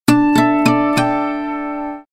02 Power Off.mp3